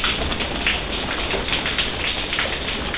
rain2.au